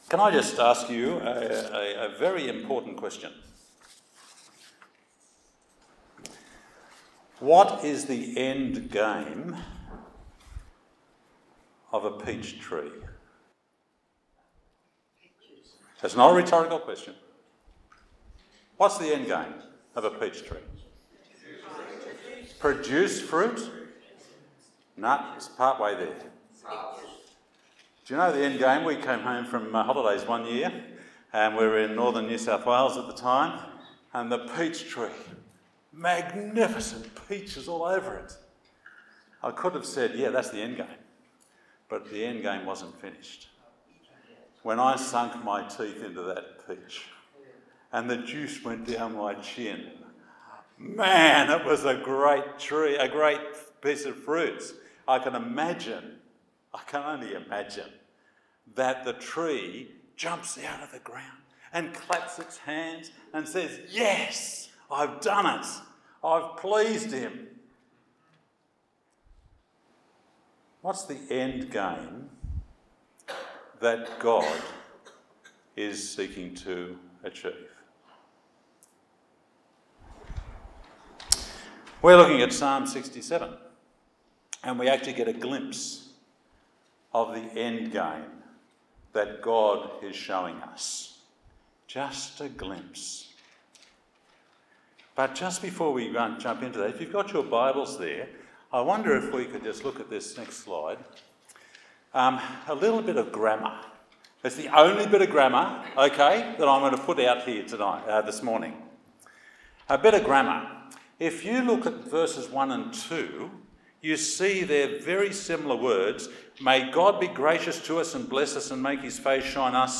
Service Type: Sunday morning service Topics: Bringing light into a dark world , Gods heart for all people